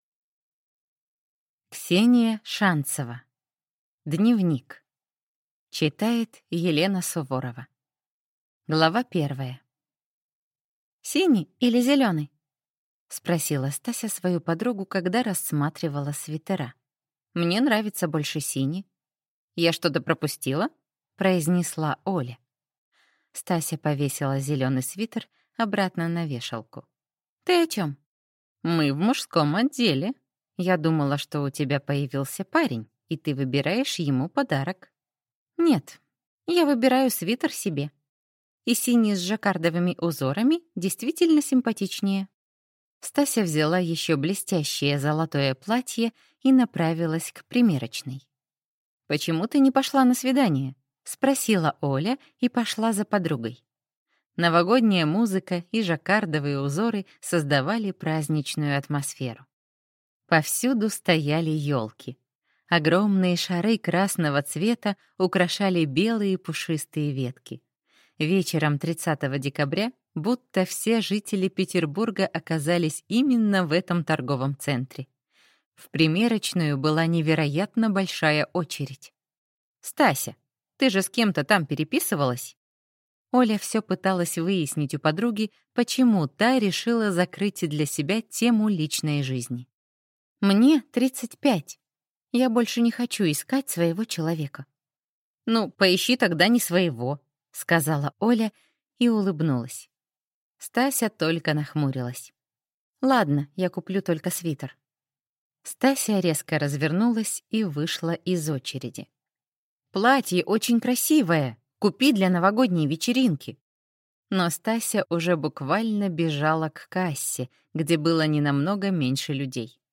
Аудиокнига Дневник | Библиотека аудиокниг
Прослушать и бесплатно скачать фрагмент аудиокниги